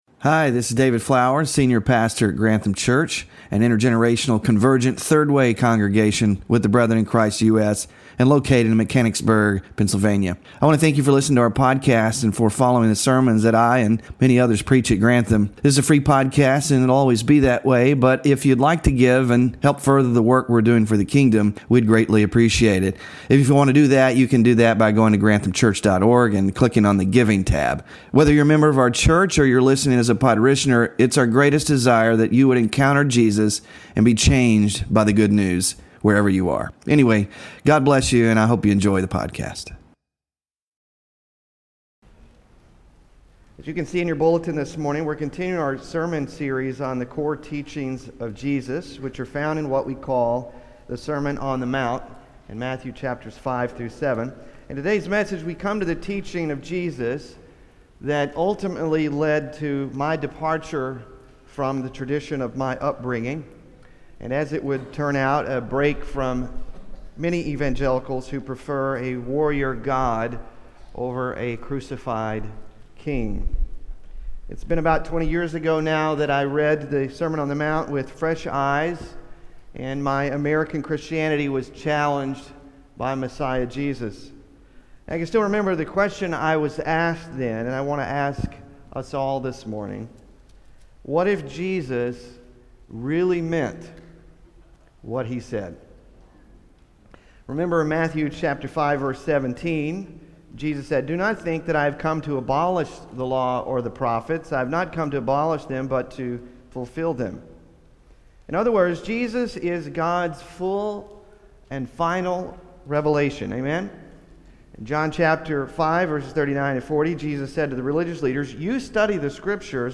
Scripture Reading: Exodus 21:23-25; Leviticus 19:18; Deut. 7:1-4; Matthew 5:38-48
WORSHIP RESOURCES Non-Retaliation and Enemy Love - Sermon Slides (9 of 10) Small Group Discussion Questions (11-02-25) Bulletin (11-02-25)